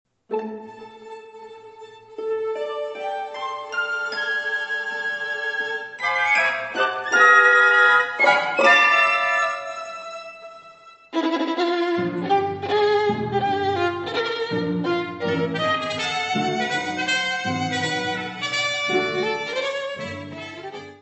Presto.